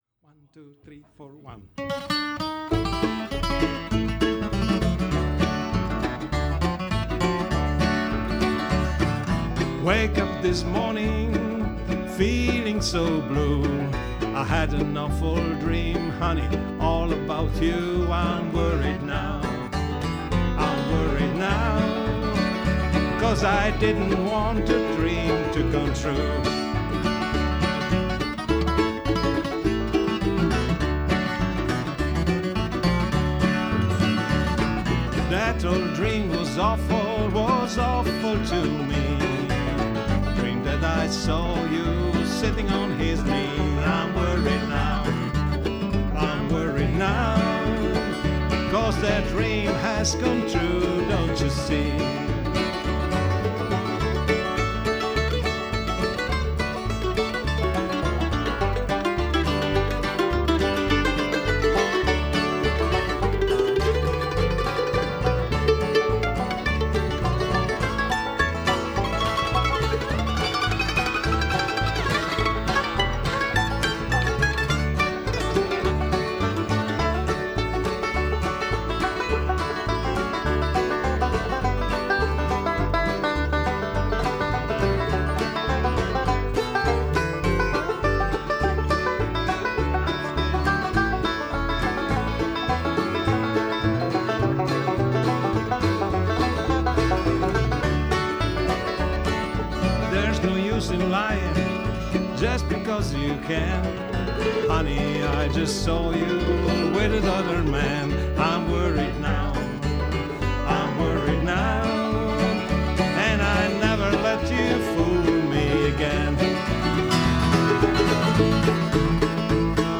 flatpicking Bluegrass
with an Italian accent is pretty cool.